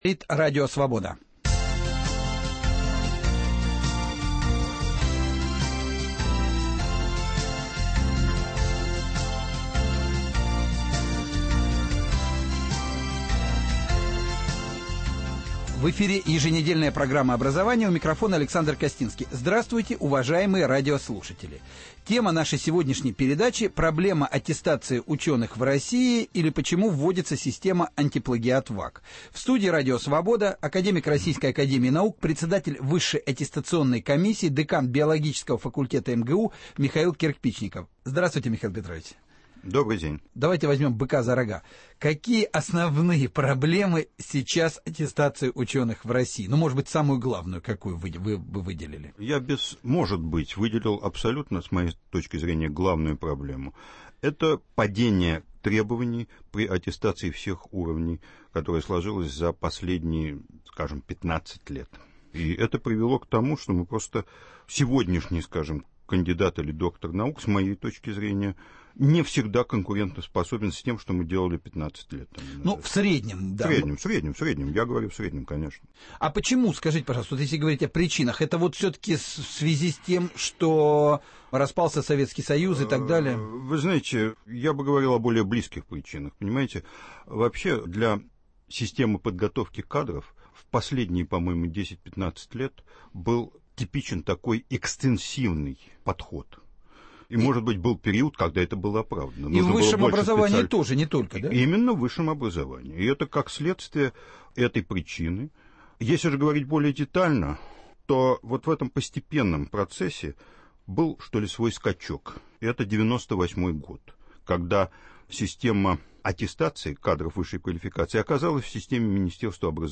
Проблема аттестации ученых в России: почему вводится система «Антиплагиат.ВАК». В студии Радио Свобода: академик Российской Академии наук, председатель Высшей аттестационной комиссии, декан биологического факультета МГУ Михаил Кирпичников